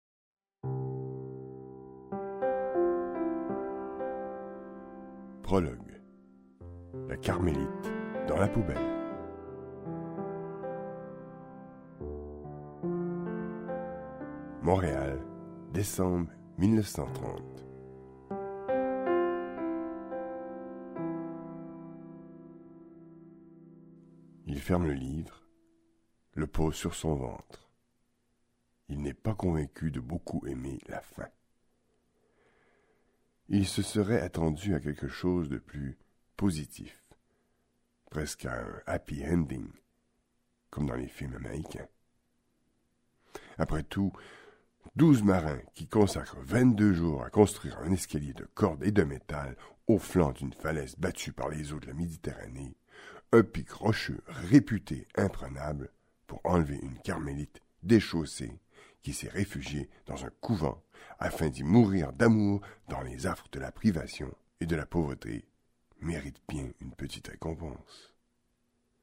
Click for an excerpt - Les clefs du Paradise de Michel Tremblay